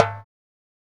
African Drum_07.wav